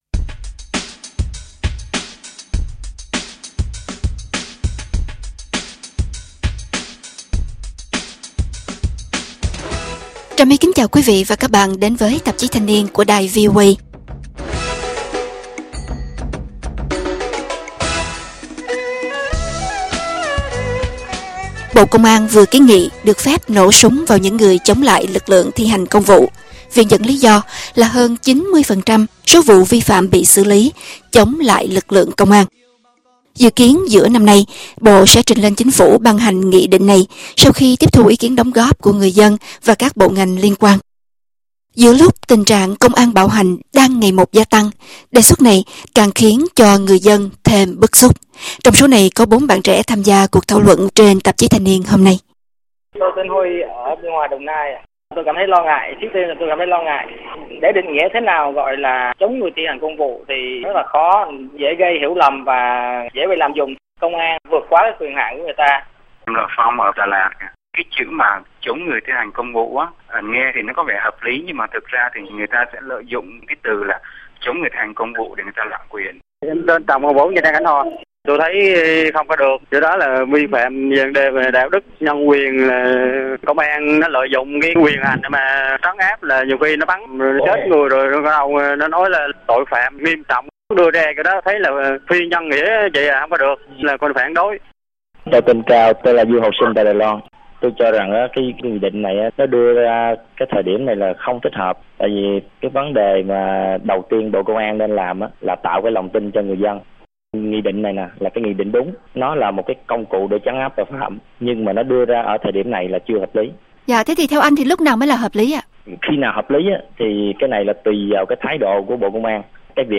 Các bạn trẻ thảo luận về kiến nghị của Bộ Công an được phép nổ súng vào những người chống lại lực lượng thi hành công vụ